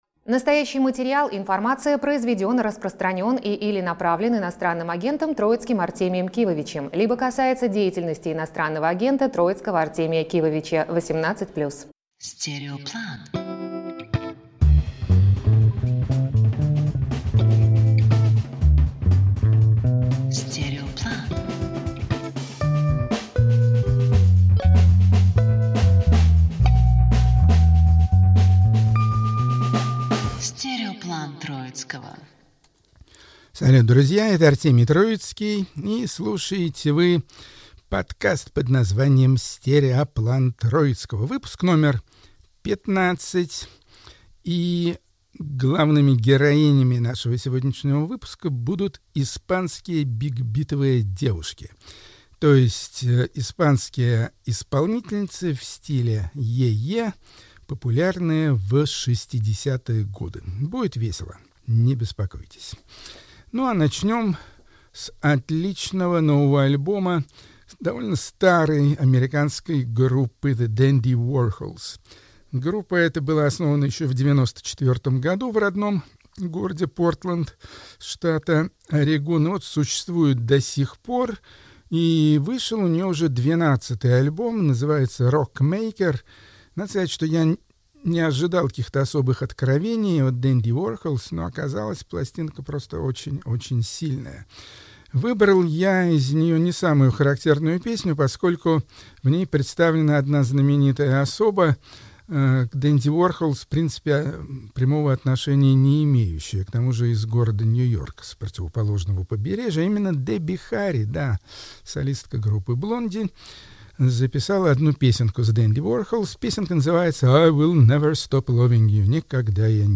Эфир ведёт Артемий Троицкий